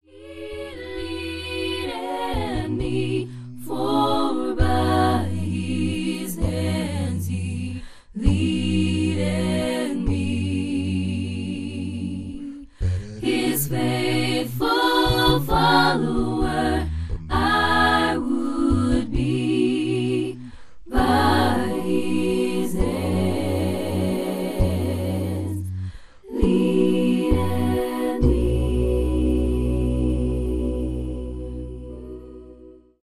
Your source for the best in A'cappella Christian Vusic ®
a cappella gospel songstress